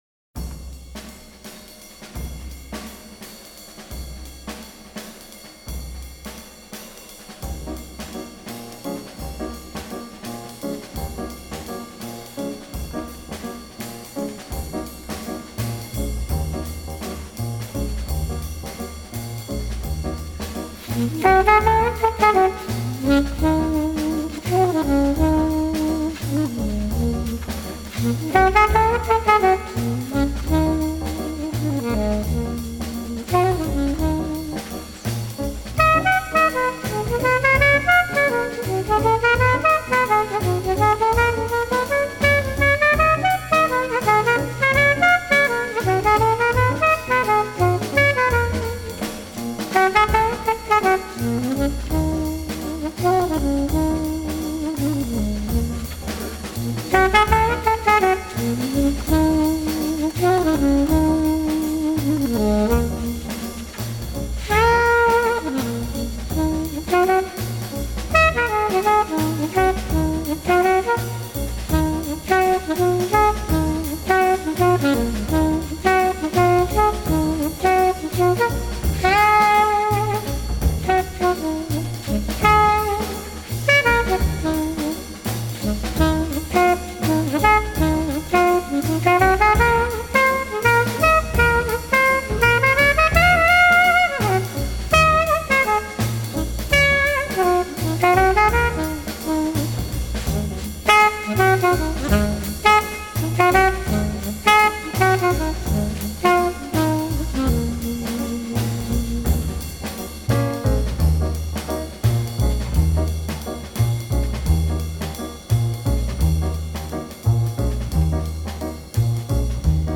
the most recognisable jazz composition for me.
quartet